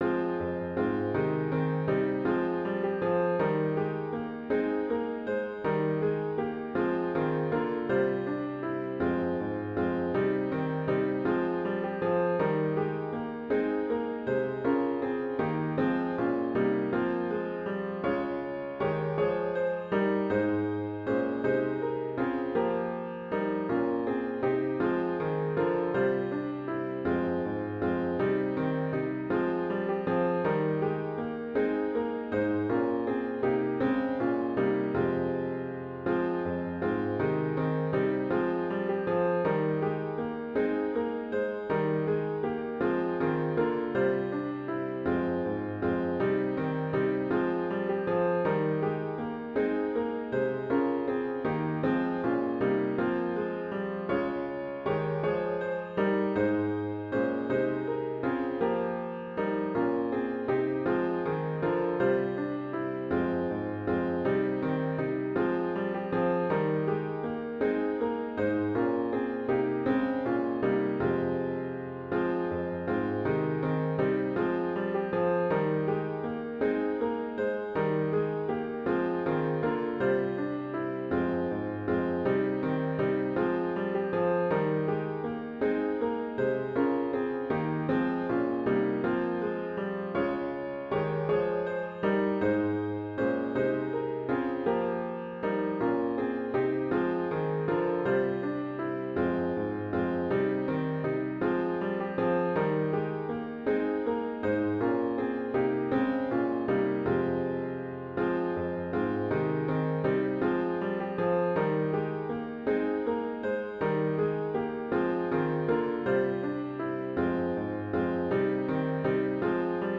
OPENING HYMN   “In an Age of Twisted Values”   GtG 345